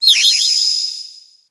Cri de Bibichut dans Pokémon HOME.